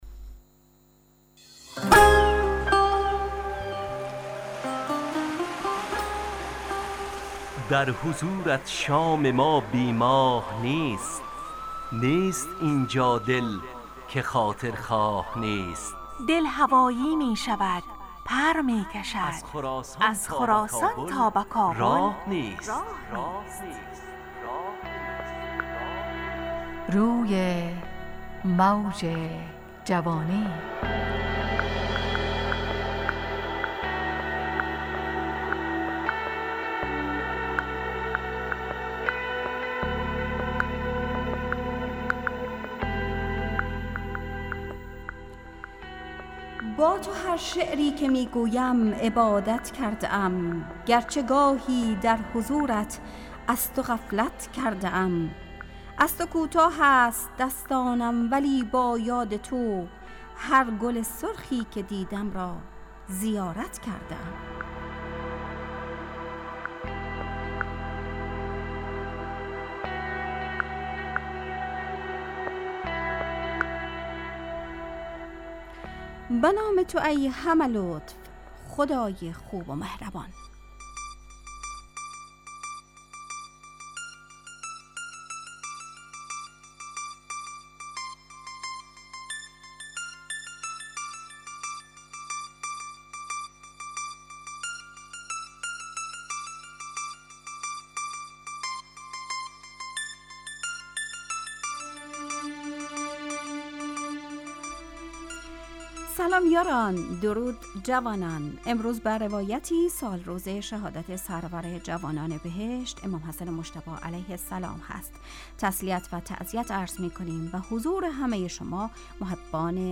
روی موج جوانی، برنامه شادو عصرانه رادیودری.
همراه با ترانه و موسیقی .